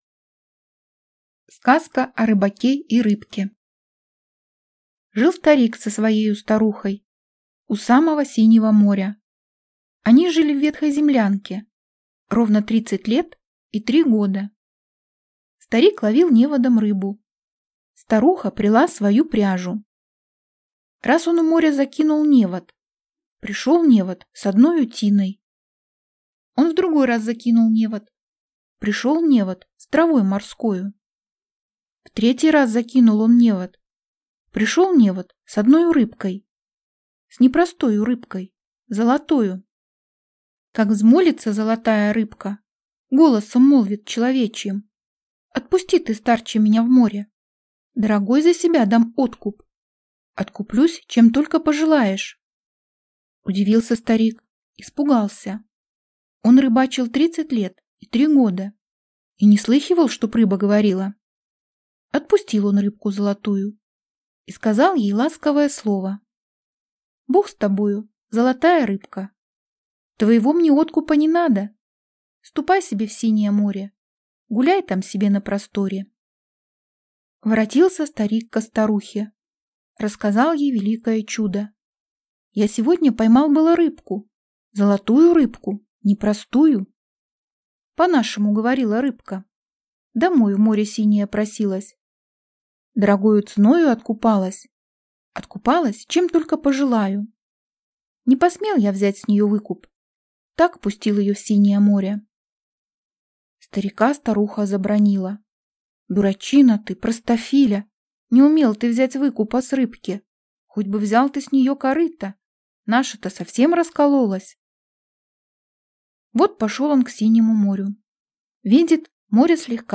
Аудиокнига Сказки. Руслан и Людмила (сборник) | Библиотека аудиокниг